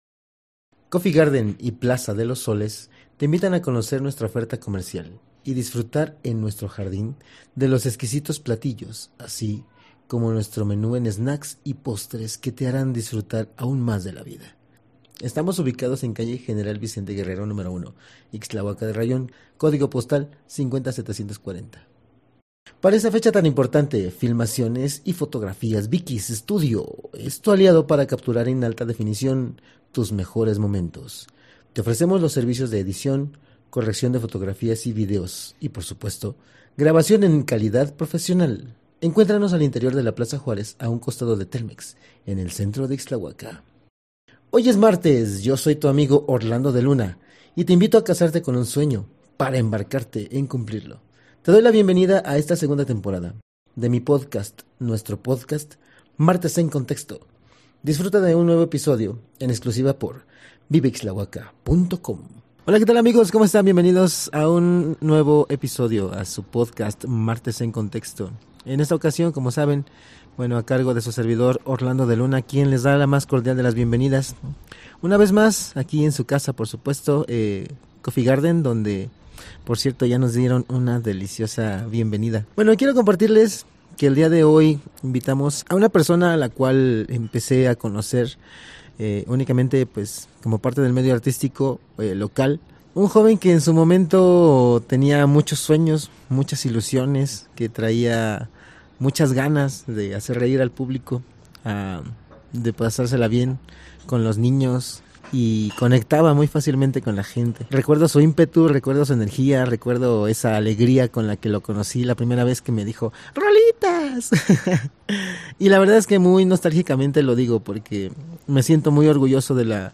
Locación: Cafetería “Coffee Garden".